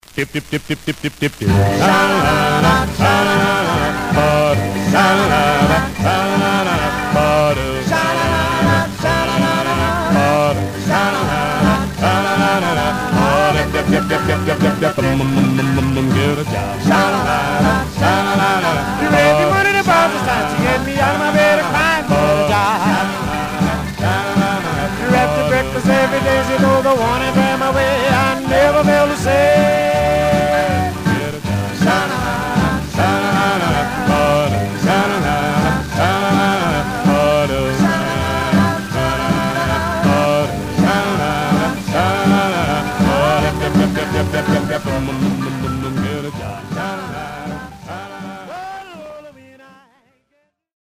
Surface noise/wear Stereo/mono Mono
Male Black Groups